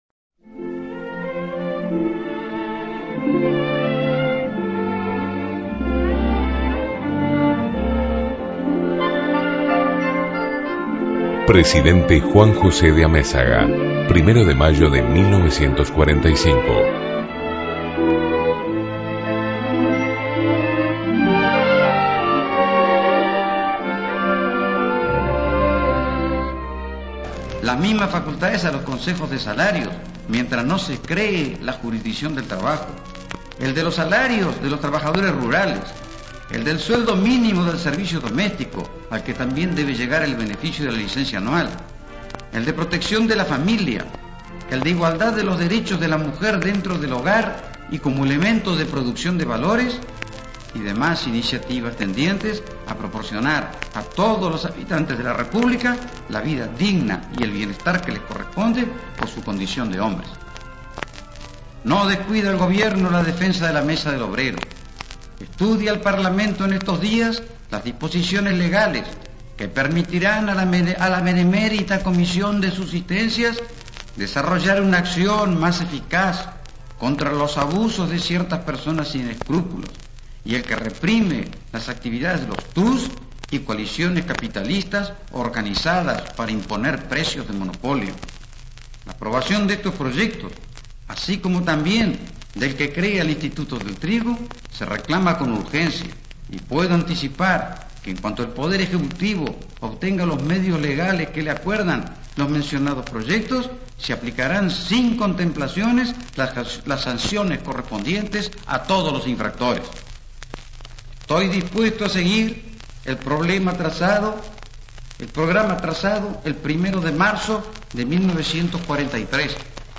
Presidente Juan José de Amézaga, mensaje a los trabajadores, 1 de mayo de 1945